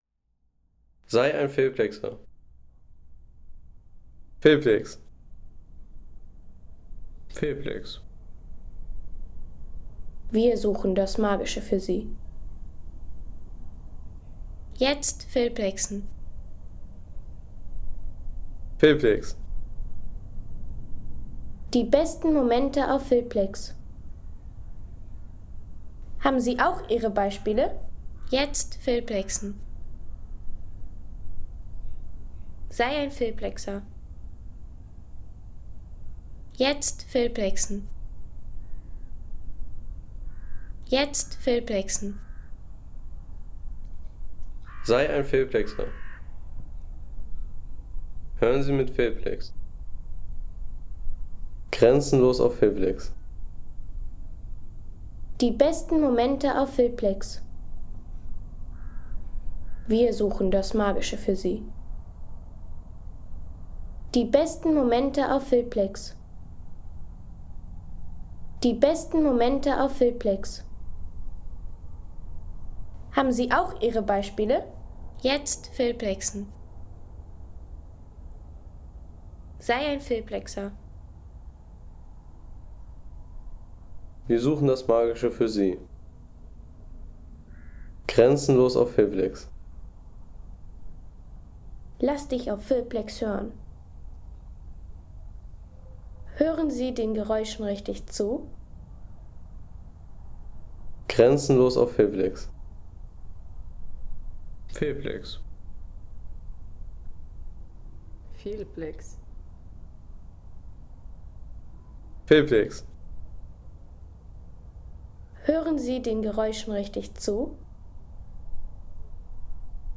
Gipfelmoment am Schöckl Home Sounds Landschaft Berge Gipfelmoment am Schöckl Seien Sie der Erste, der dieses Produkt bewertet Artikelnummer: 269 Kategorien: Landschaft - Berge Gipfelmoment am Schöckl Lade Sound.... Gipfelmoment am Schöckl – Auf 1.445 Metern über dem Alltag.